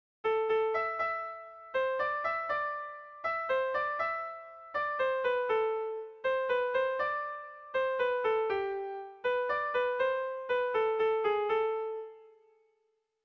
Bertso melodies - View details   To know more about this section
Erlijiozkoa
ABDE